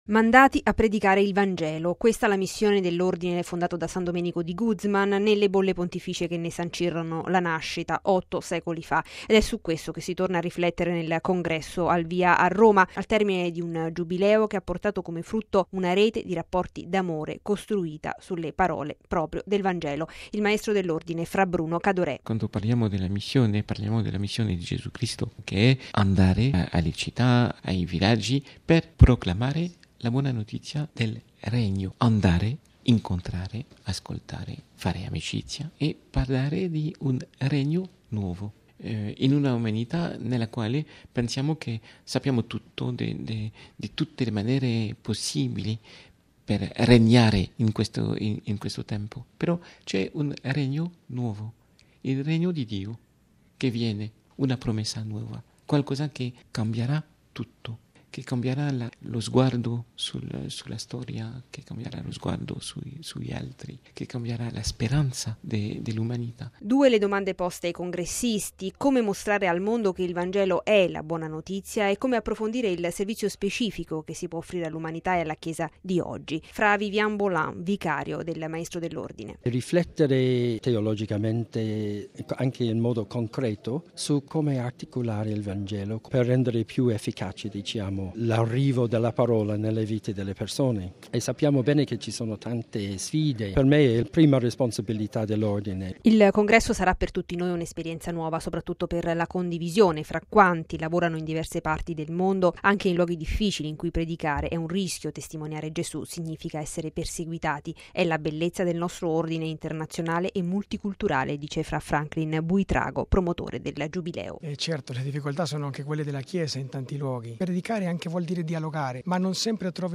Il Congresso chiude il Giubileo per gli 800 anni di fondazione dell’Ordine in seno alla Chiesa cattolica. Il servizio